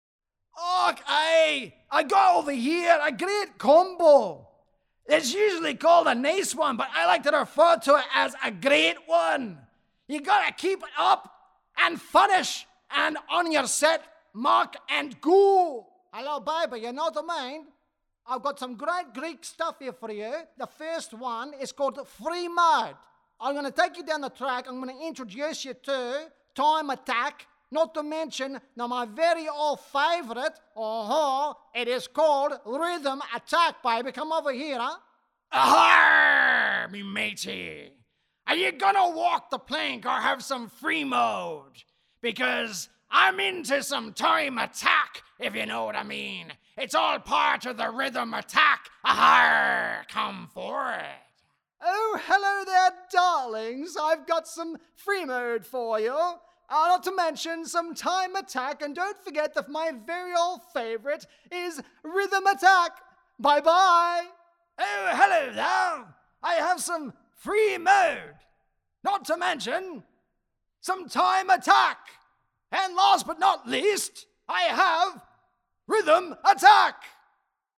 Animated, Commercial and Narration.
Animated Voices
American, British, Scottish, Irish, Australian, New Zealand, South African, Eastern European
Middle Aged